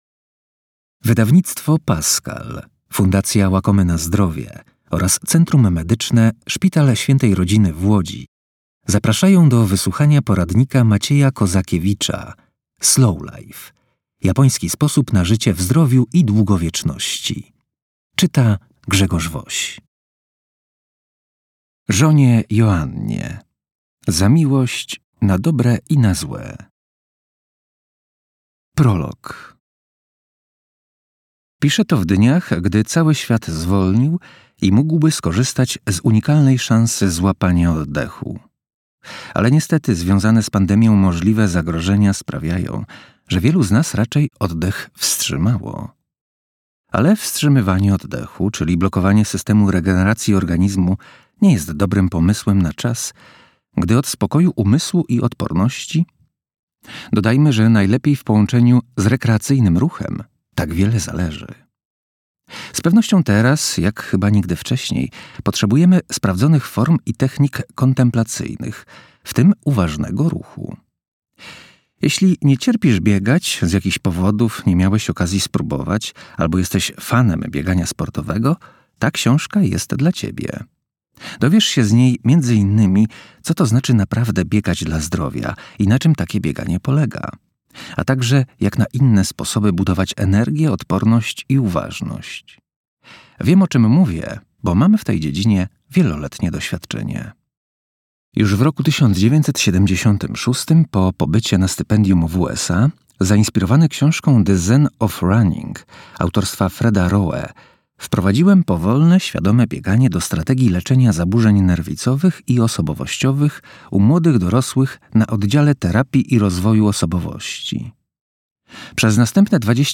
fragmeny audiobooka